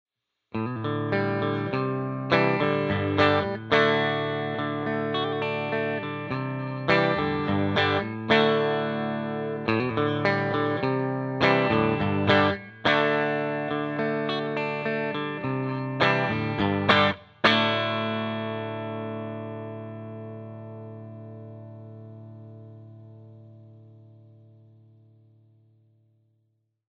50R and 55T used together (middle position)
50R-55T neck and bridge.mp3